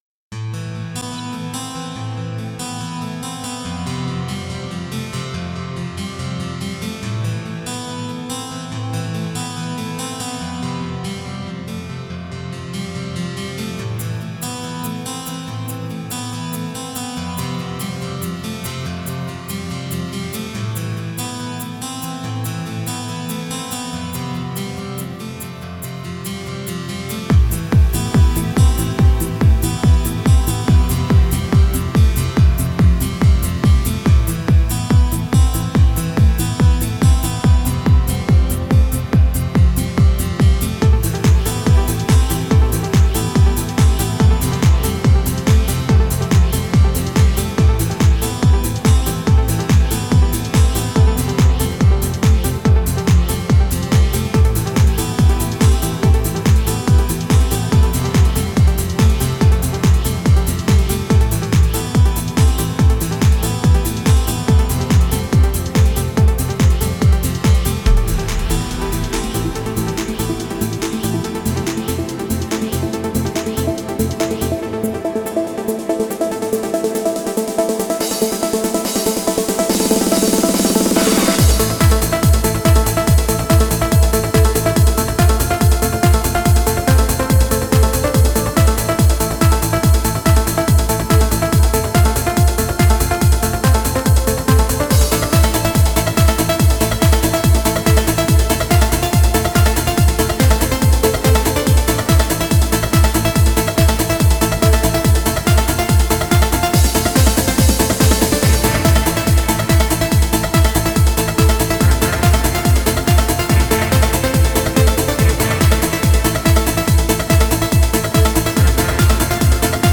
یه اهنگ قشنگ تو سبک الکترونیک